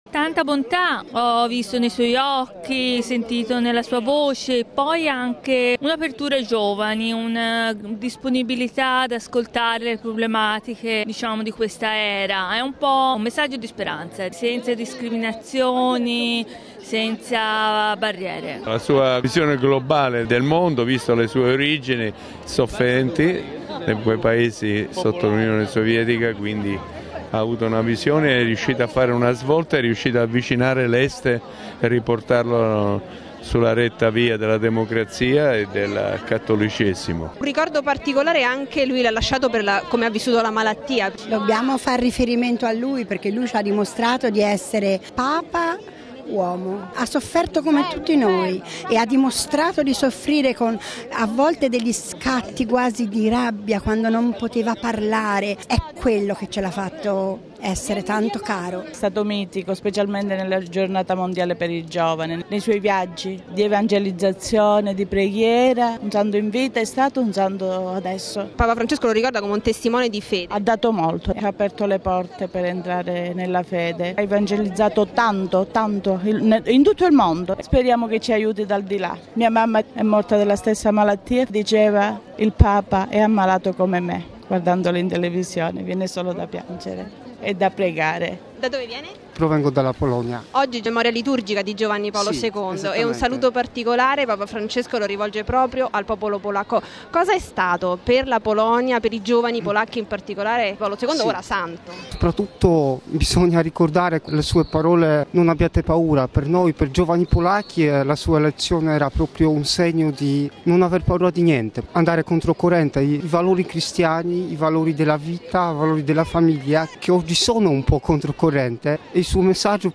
Ma ascoltiamo dai fedeli presenti in Piazza San Pietro cosa ricordano di Papa Wojtyła: